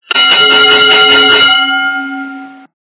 При прослушивании Звук - Боксерский гонг (конец боя) качество понижено и присутствуют гудки.
Звук Звук - Боксерский гонг (конец боя)